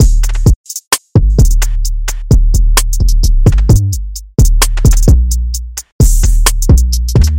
循环鼓动陷阱与808
Tag: 135 bpm Trap Loops Drum Loops 1.20 MB wav Key : Unknown